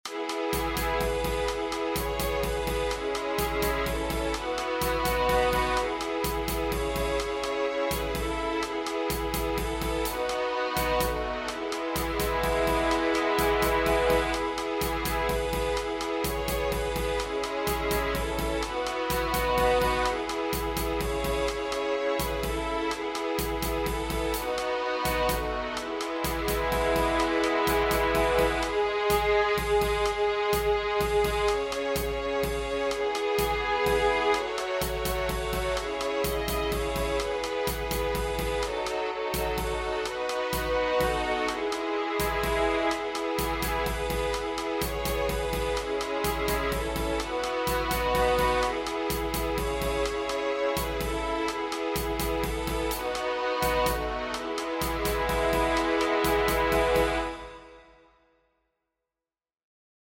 • Catégorie : Chants de Méditation